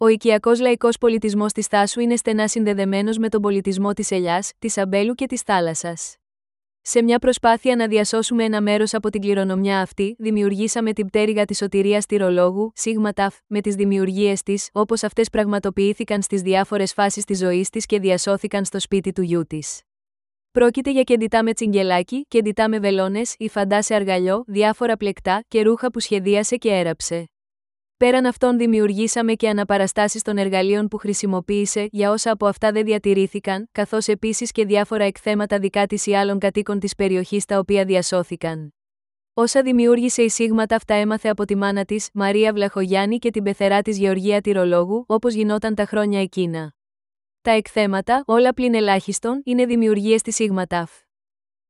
Ηχητική ξενάγηση